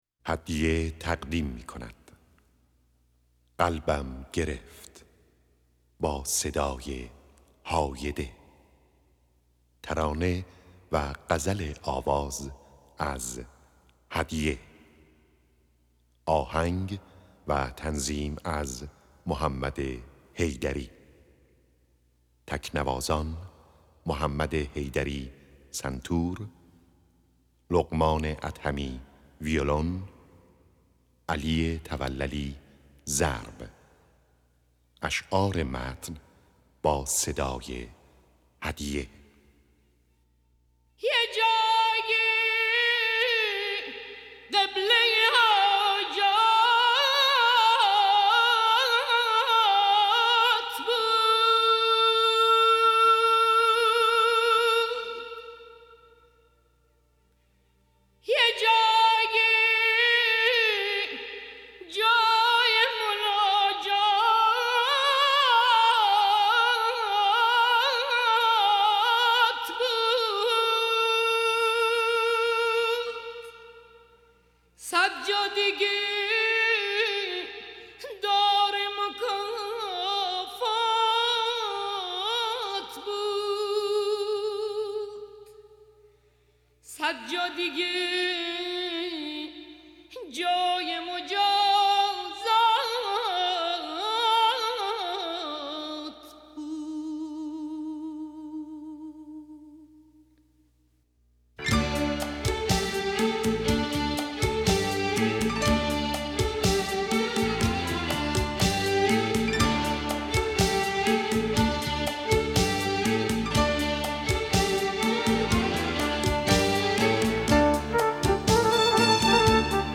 سنتور
ویولن
ضرب